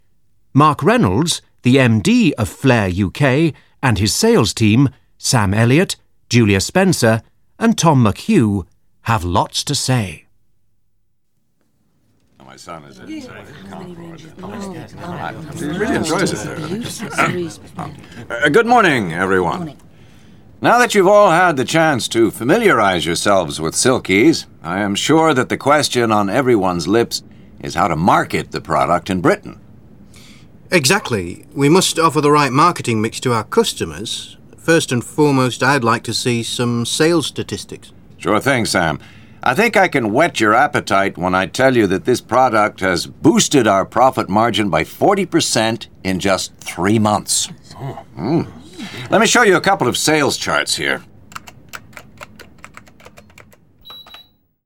Audio/Hörbuch
• Zehn hörspielartige Dialogszenen mit den wichtigsten Gesprächssituationen in Präsentationen und Moderationen auf Audio-CD und als MP3-Download.
• Britisches und amerikanisches Englisch.